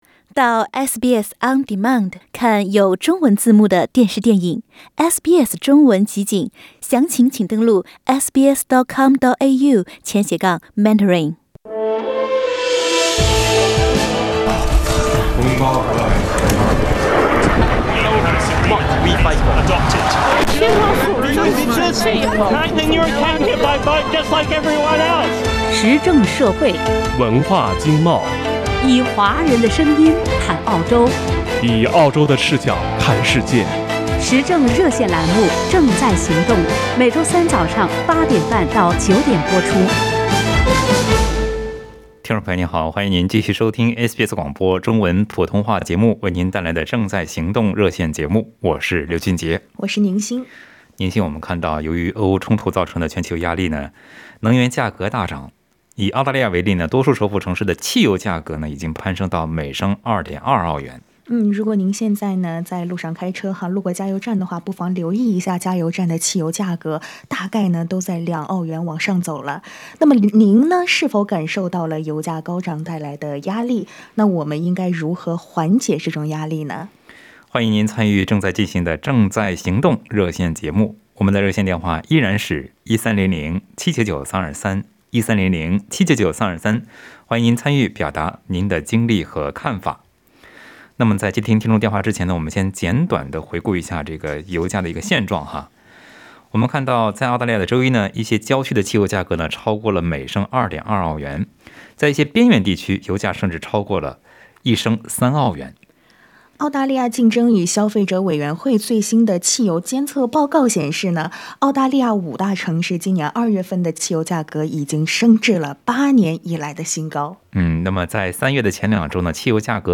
请您点击收听本期《正在行动》热线节目的完整内容。